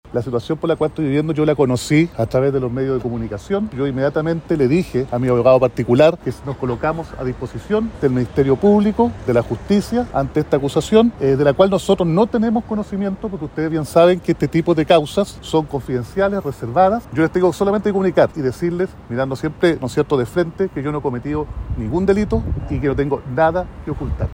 A primera hora de este jueves, la autoridad se refirió por primera vez frente a los medios de comunicación respecto a la denuncia e investigación en su contra por el delito de violación a de una funcionaria municipal, en hechos que habrían ocurrido a fines de 2021.
“No he cometido ningún delito y no tengo nada que ocultar”, aseveró el jefe comunal.